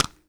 Clap13.wav